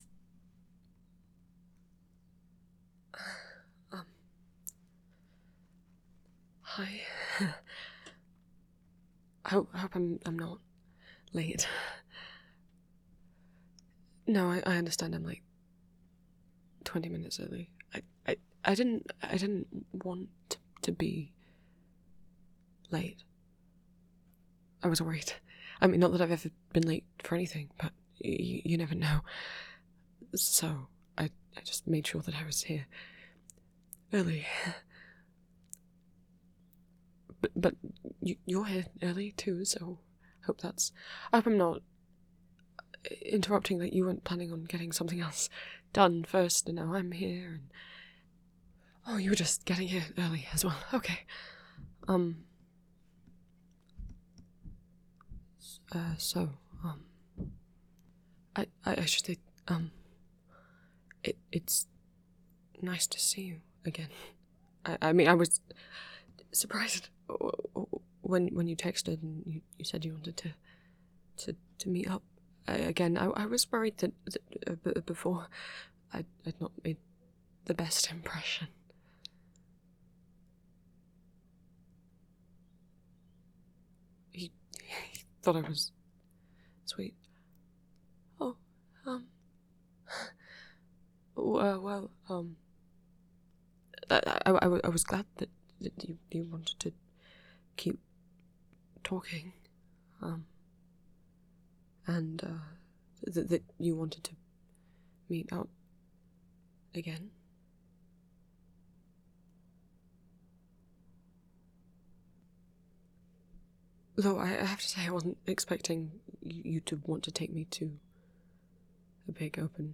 [TM4A] [Second date] [Romantic] [Flirting]